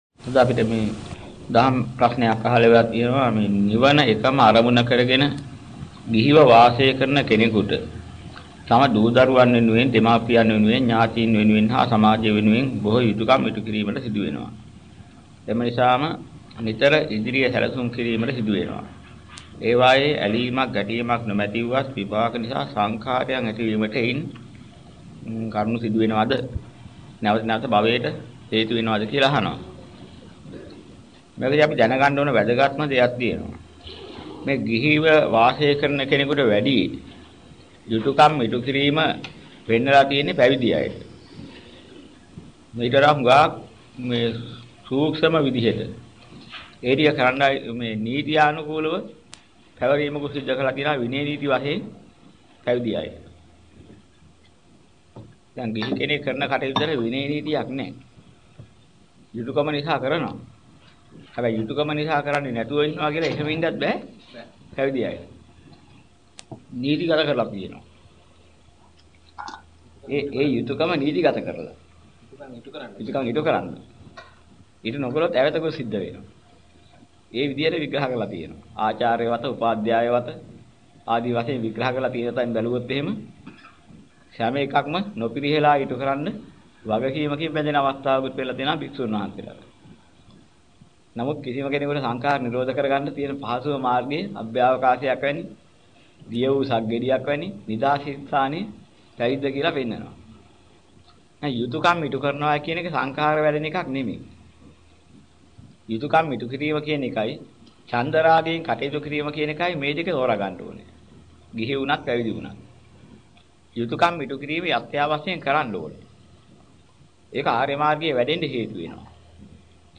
ධර්ම දේශනා.